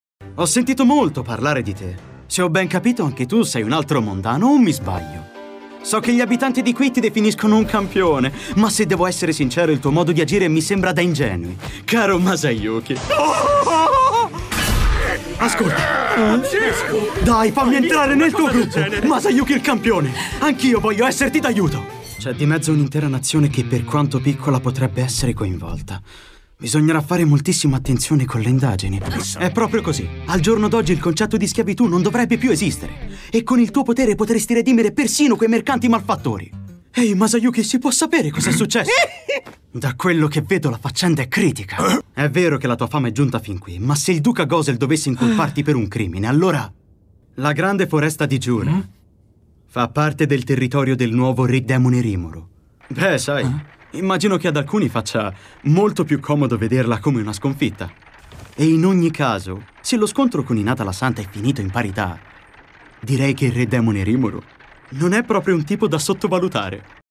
Il mondo dei doppiatori
FILM D'ANIMAZIONE (CINEMA E HOME-VIDEO)